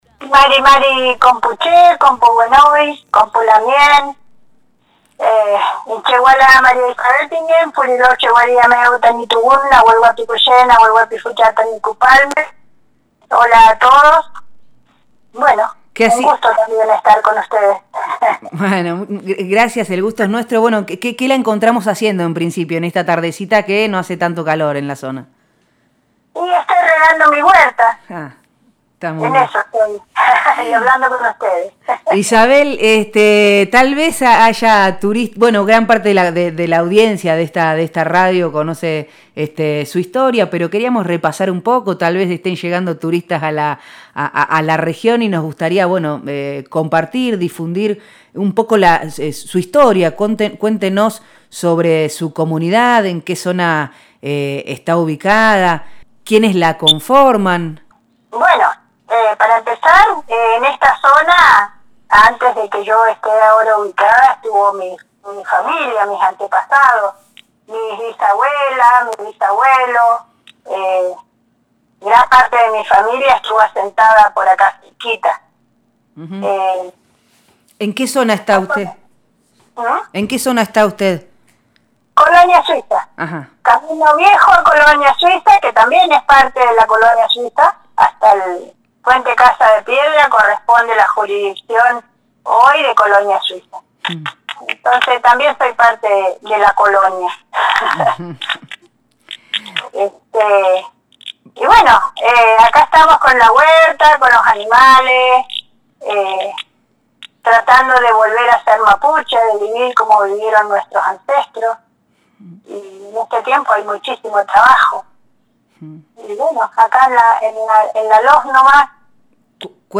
se sumó al aire de Nosotres les Otres (jueves de 18 a 21 por fm94.5). Relató su historia de vida y de lucha, y se expresó en relación al ataque constante contra los pueblos originarios.